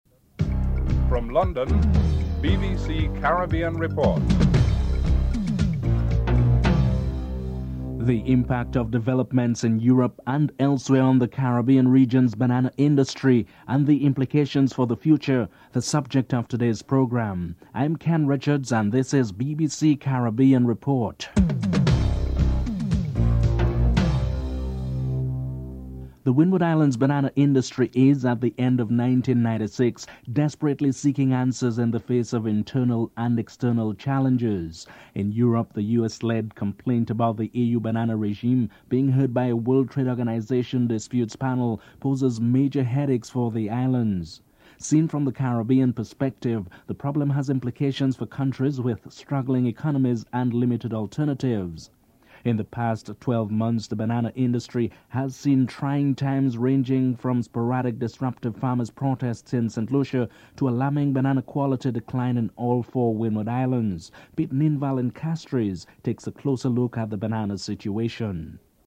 Headlines (00:00-00:23)
Caricom Chairman Lester Bird says that the region cannot afford to give up the banana battle.